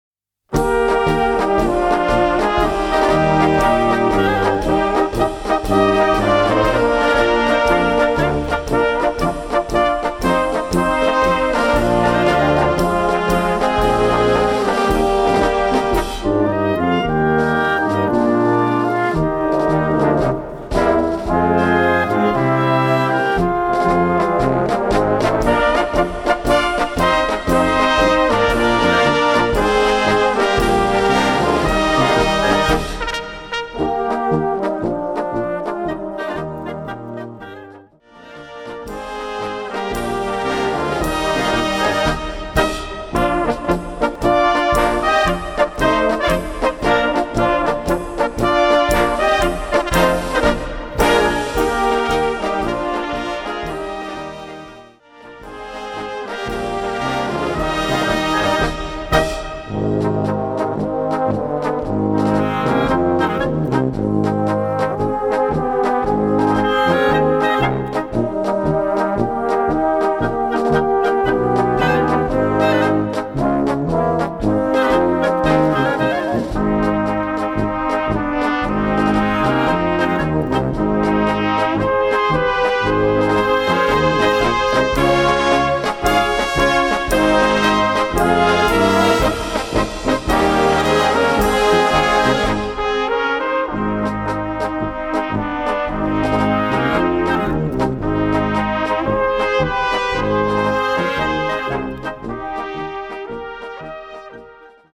Gattung: Polka
A4 Besetzung: Blasorchester Zu hören auf